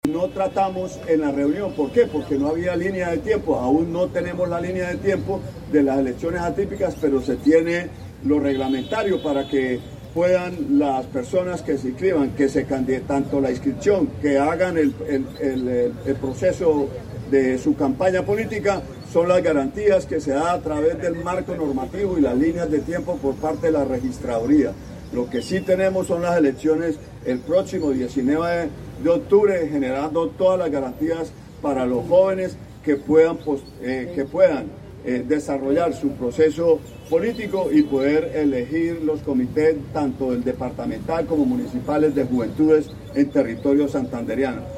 Óscar Hernández, secretario del Interior de Santander